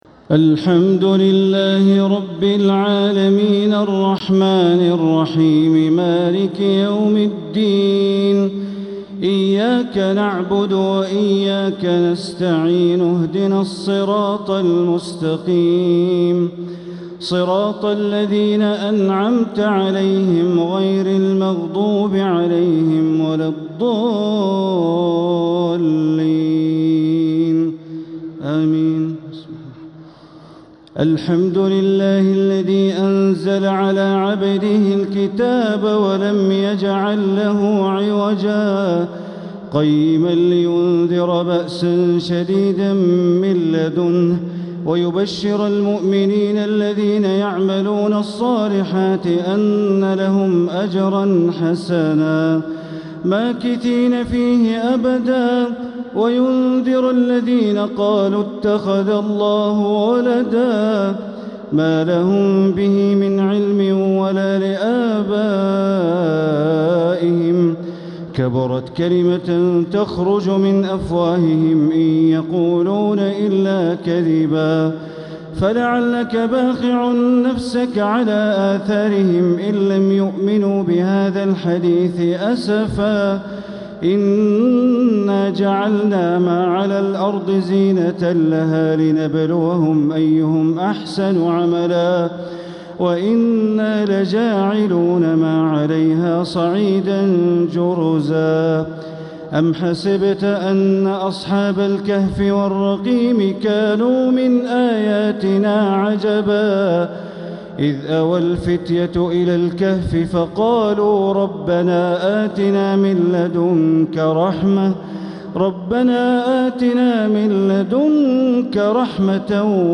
تراويح ليلة 20 رمضان 1447هـ من سورة الكهف ( 1-82) > 1447هـ > الفروض - تلاوات بندر بليلة